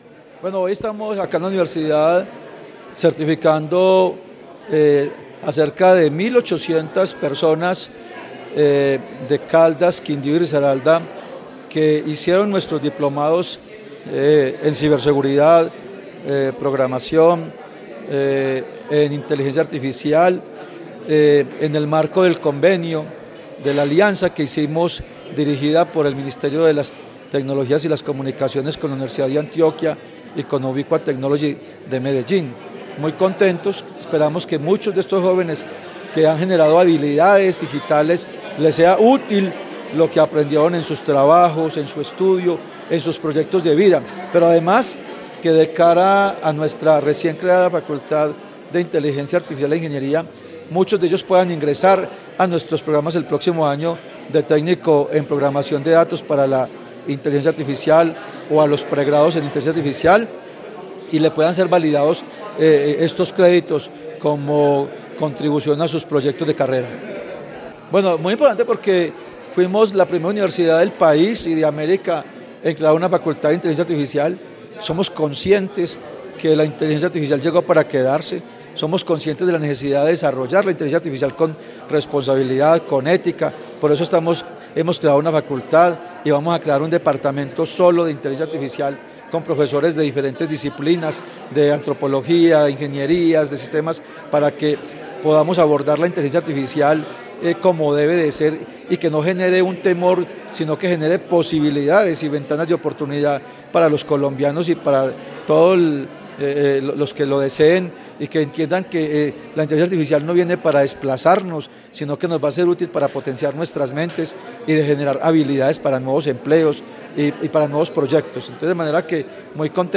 Audio rector de la Universidad de Caldas, Fabio Hernando Arias Orozco.